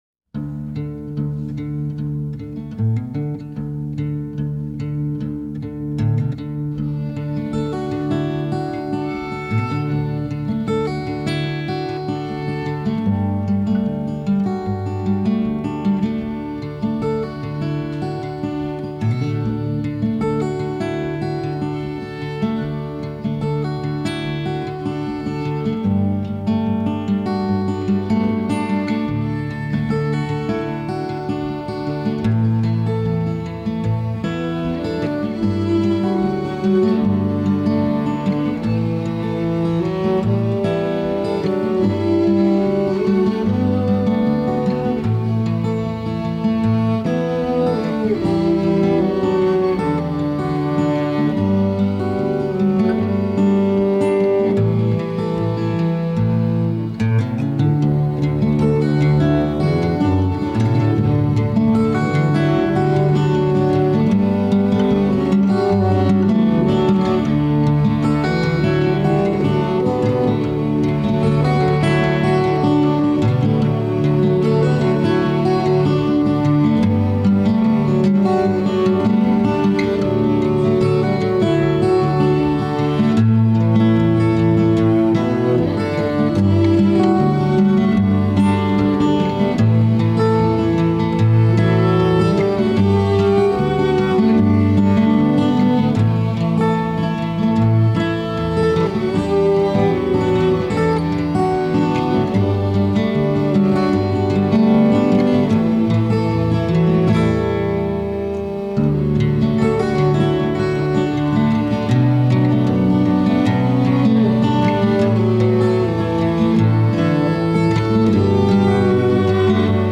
vocals, cello, keyboards, acoustic bass, guitar
guitars, cuatro/charango, Irish bouzouki, vihuela, saxophone